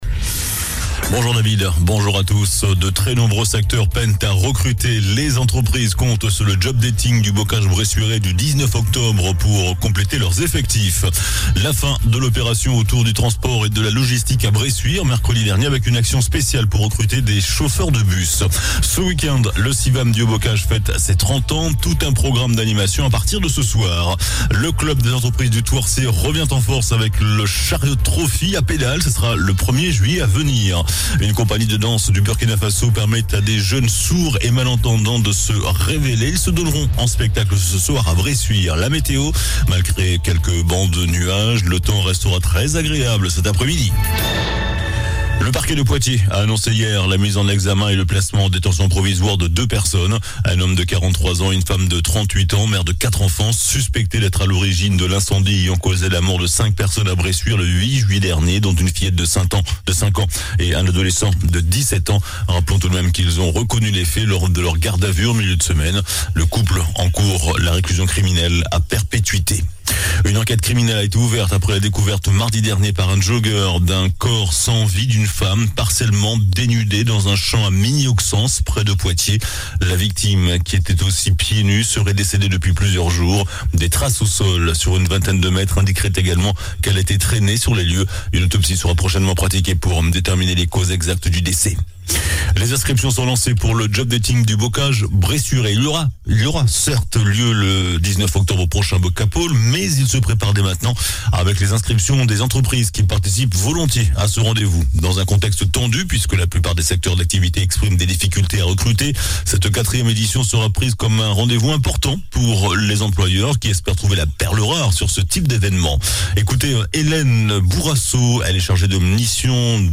JOURNAL DU VENDREDI 16 JUIN ( MIDI )